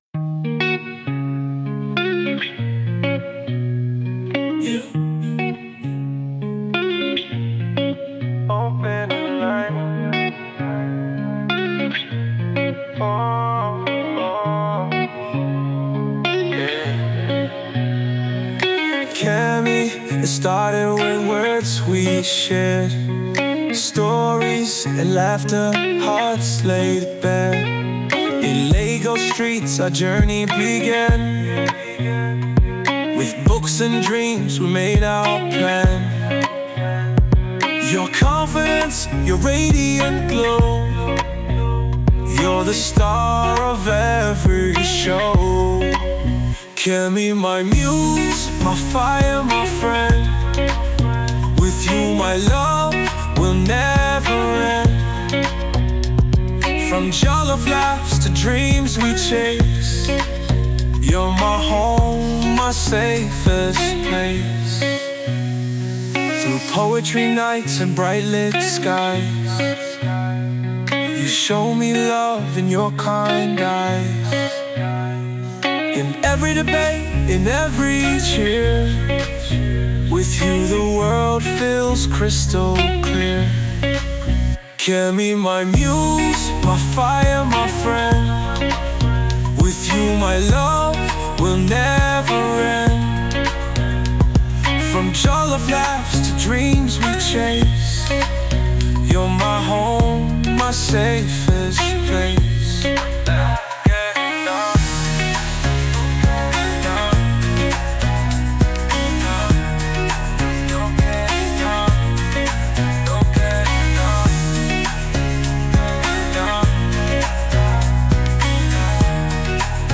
soulful song